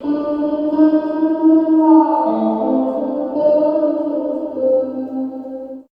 95 GTR 1  -L.wav